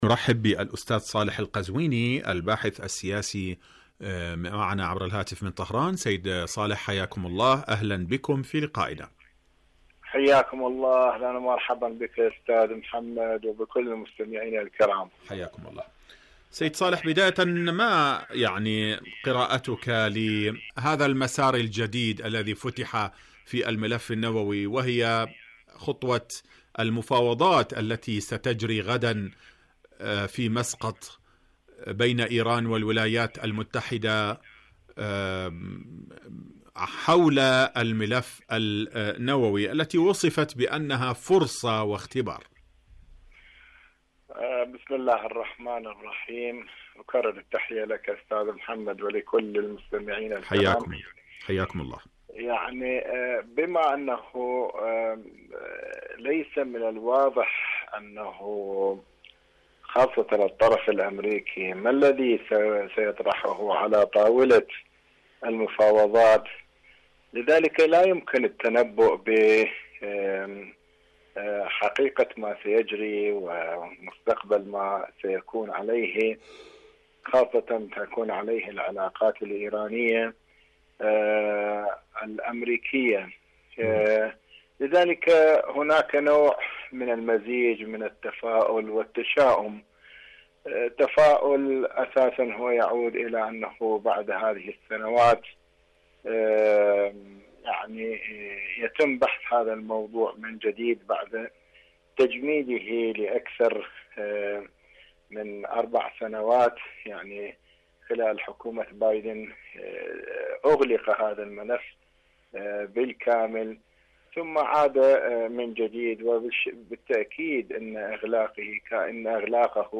مقابلات برامج إذاعة طهران العربية برنامج حدث وحوار مقابلات إذاعية محادثات مسقط فرصة واختبار محادثات مسقط شاركوا هذا الخبر مع أصدقائكم ذات صلة الشباب والمشاركة في المسابقات القرآنية..